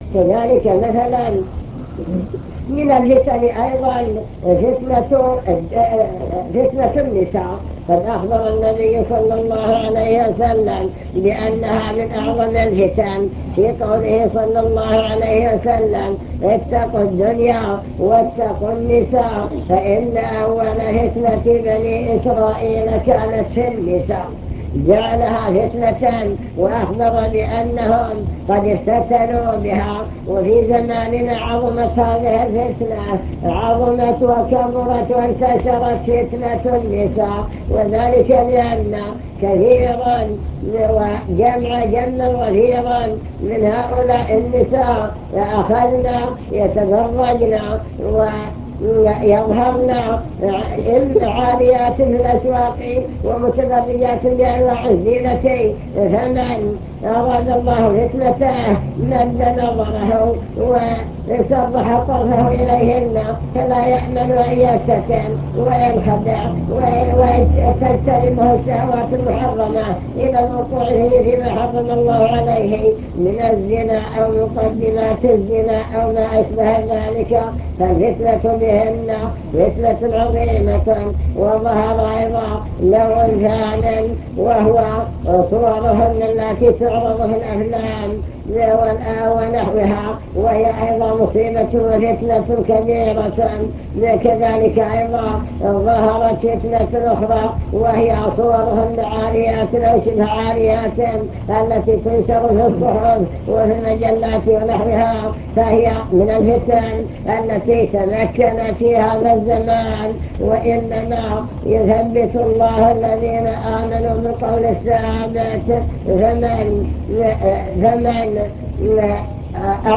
المكتبة الصوتية  تسجيلات - محاضرات ودروس  محاضرة في فتن هذا الزمان ومقاومتها